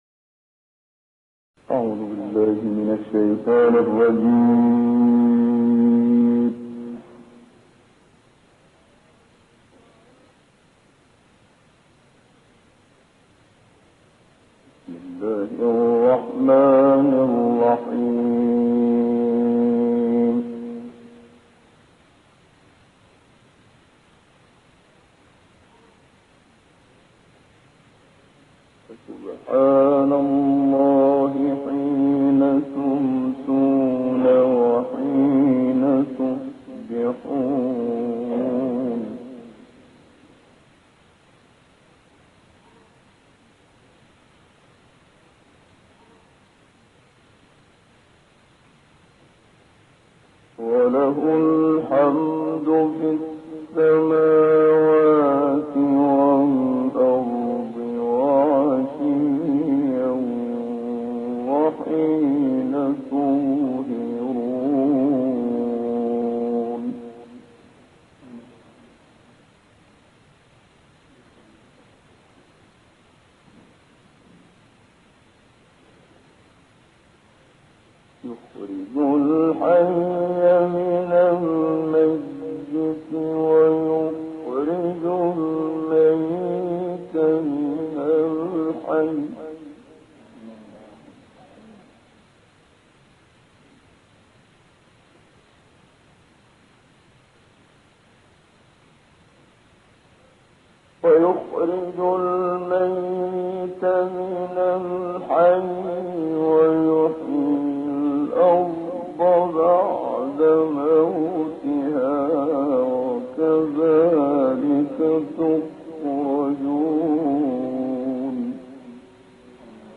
تلاوت سوره روم با صدای استاد منشاوی + دانلود/ تسبیح و حمد در همه حال براى خدا است
گروه فعالیت‌های قرآنی: قطعه‌ای شنیدنی از تلاوت استاد محمد صدیق منشاوی از آیات ۱۷-۵۴ سوره روم ارائه می‌شود.